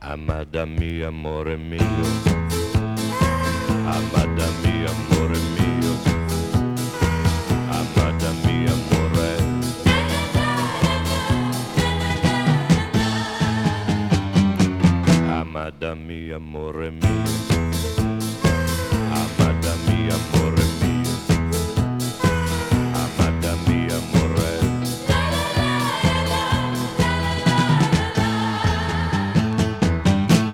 • Качество: 211, Stereo
веселые